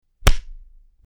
Punch
Punch.mp3